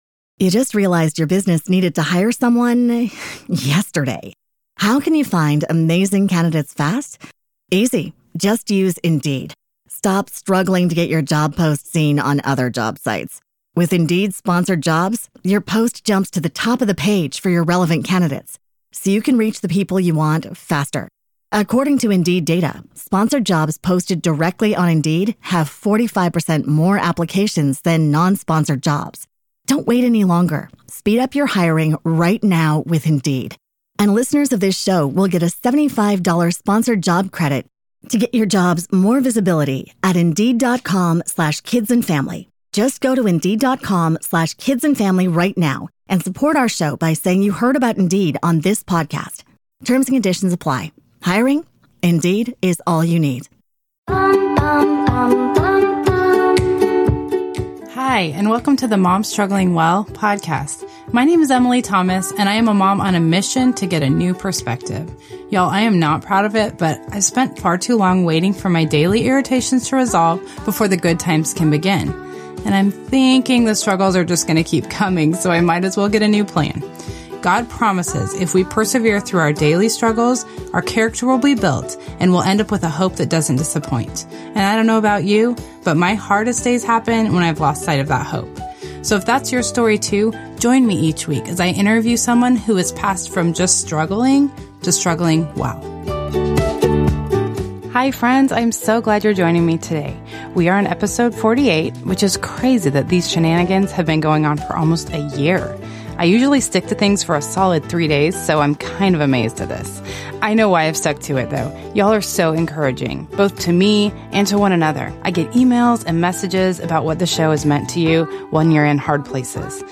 She's one of my personal favorites so I had to try really hard not to be super gushy about that during the interview.